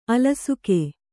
♪ alasuke